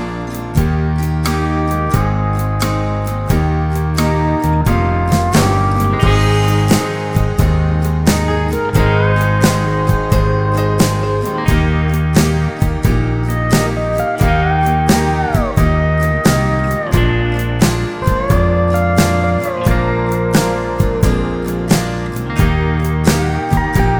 no Backing Vocals Country (Male) 3:32 Buy £1.50